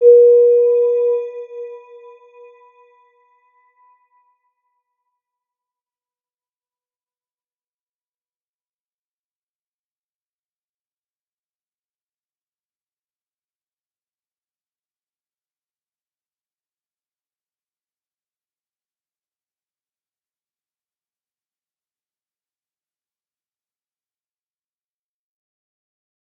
Round-Bell-B4-mf.wav